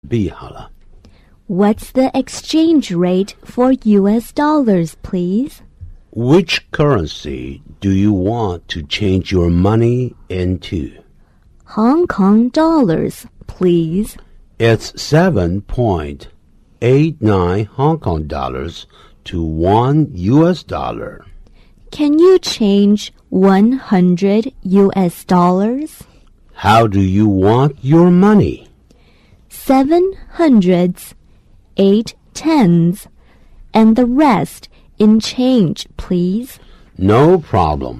Dialogue B